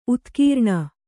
♪ utkīrṇa